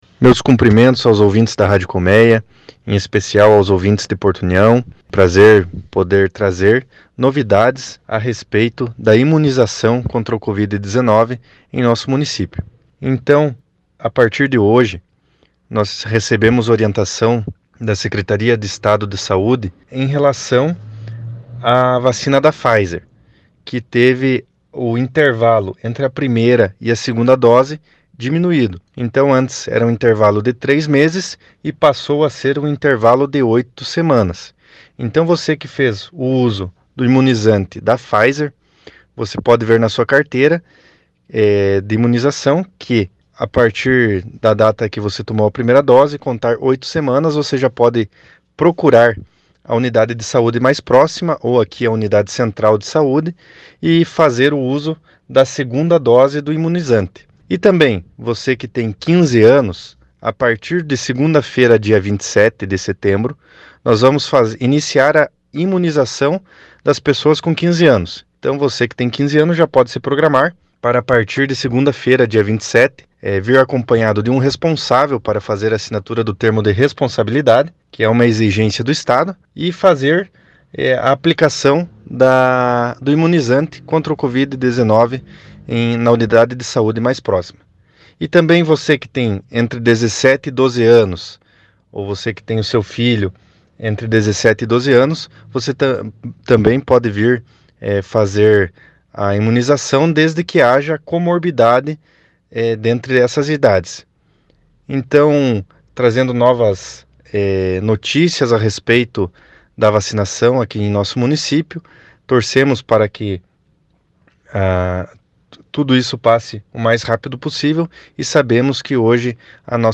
Acompanhe o áudio do secretário de Saúde abaixo: